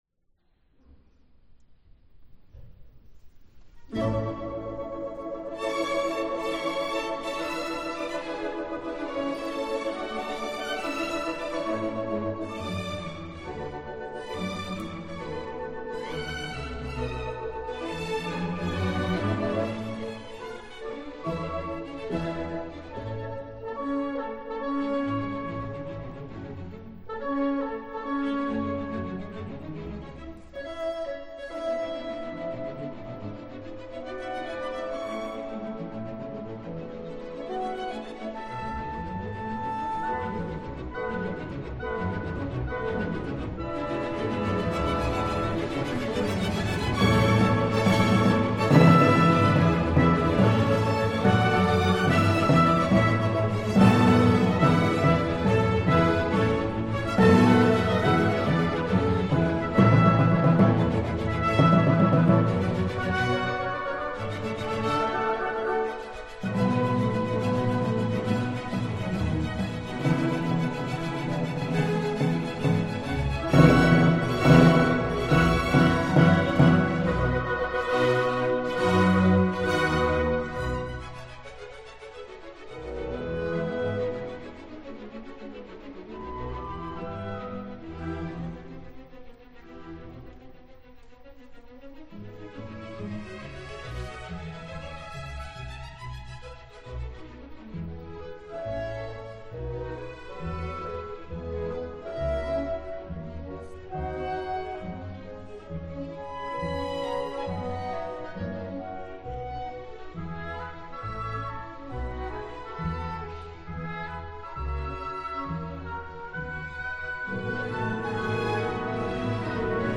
Saison Symphonique